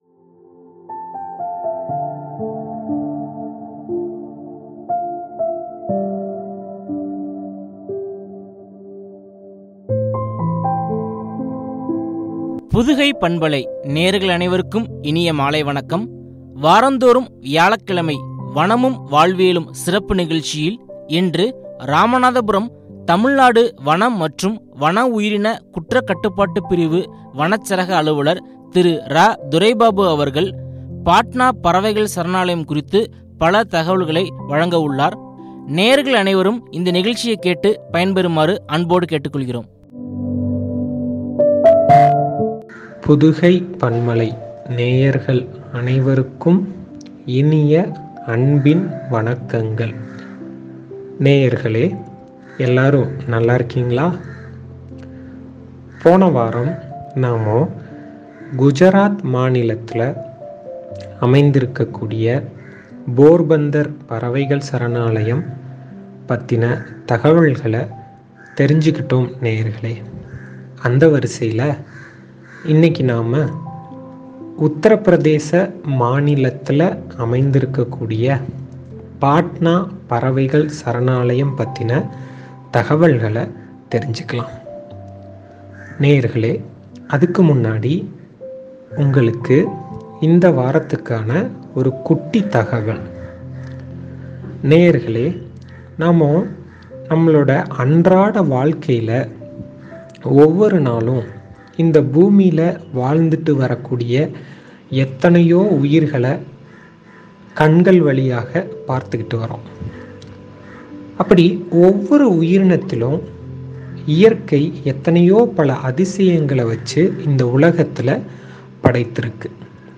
குறித்து வழங்கிய உரையாடல்.